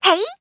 文件 文件历史 文件用途 全域文件用途 Chorong_atk_03.ogg （Ogg Vorbis声音文件，长度0.4秒，175 kbps，文件大小：9 KB） 源地址:地下城与勇士游戏语音 文件历史 点击某个日期/时间查看对应时刻的文件。
Chorong_atk_03.ogg